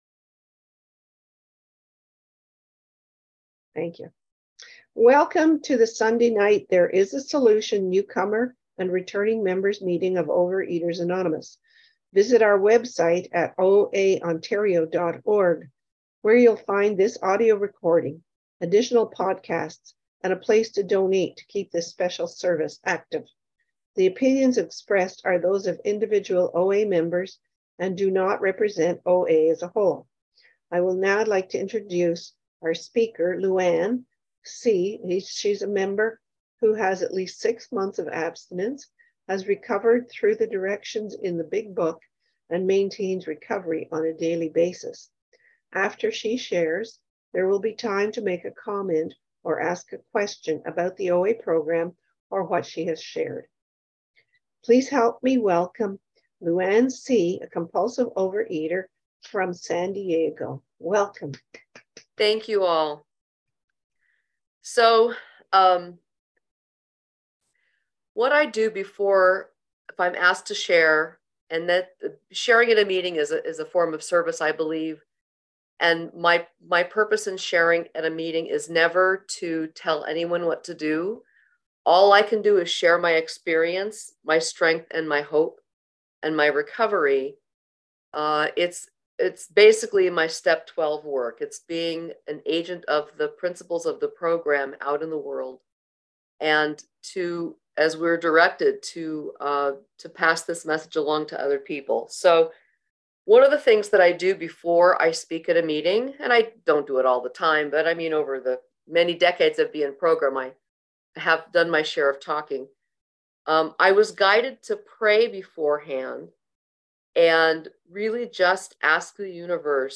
Overeaters Anonymous Central Ontario Intergroup Speaker Files OA Newcomer Meeting